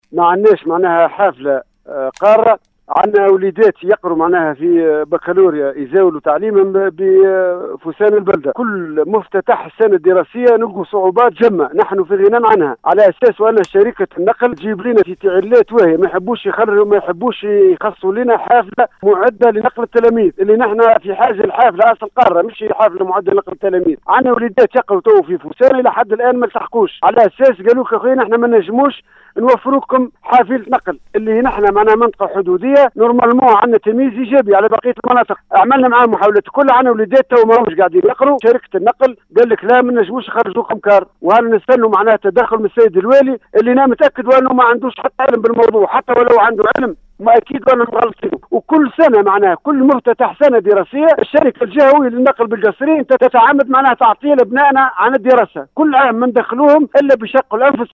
و وفق تصريح لأحد المواطنين في اتصال بإذاعة السيليوم أ ف أم فإنّ المواطنين  والأولياء  بالمنطقة قد اتصلوا عديد المرات بالشركة الجهوية للنقل بالقصرين لتوفير حافلة قارة لنقل التّلاميذ إلاّ انّه لم يتم الإستجابة إلى مطلبهم كما تمّ إعلام السلط الجهوية لكن ما يزال الوضع على حاله .
مواطن-من-متساكني-منطقة-بو-درياس.mp3